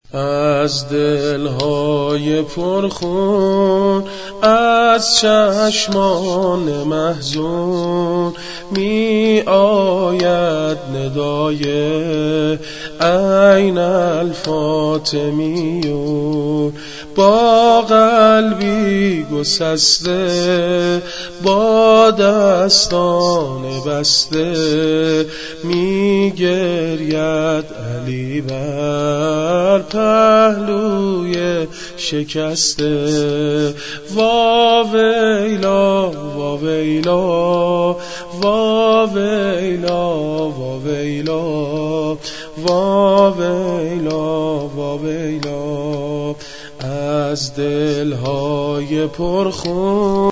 فاطمه الزهرا شهادت نوحه